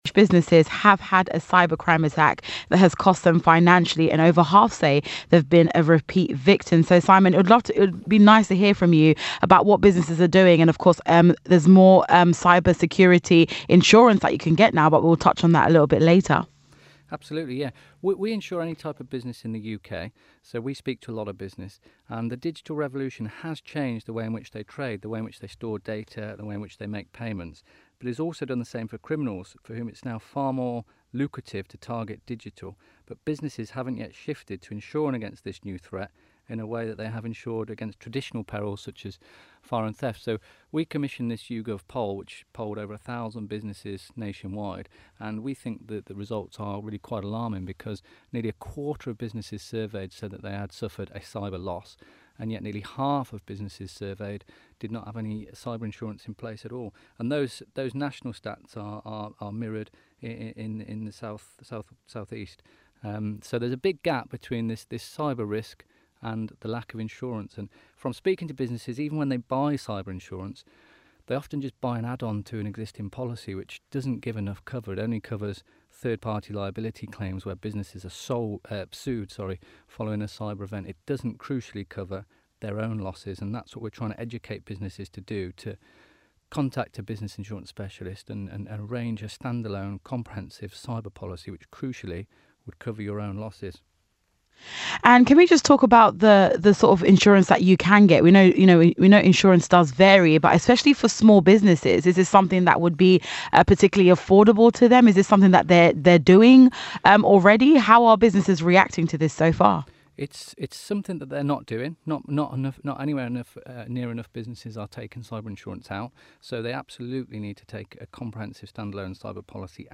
Eagle radio feature SJL Insurance’s cyber crime and cyber insurance research